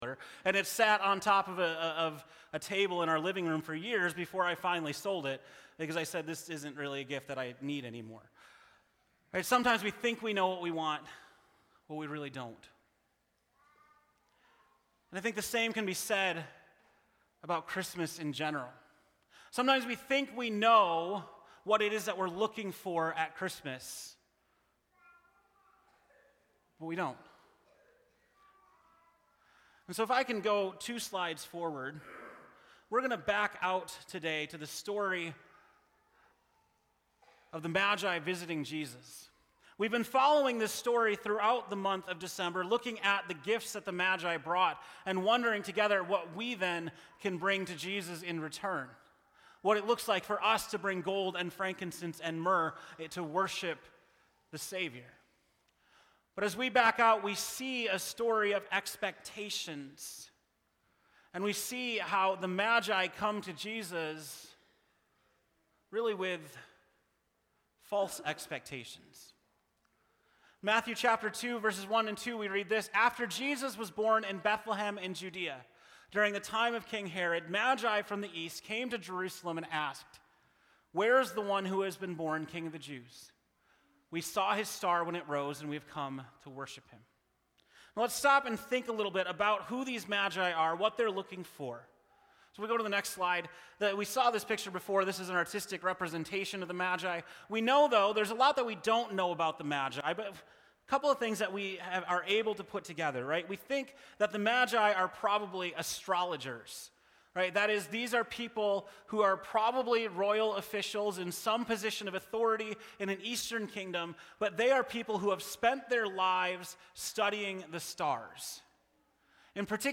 December 25, 2019 (Morning Worship)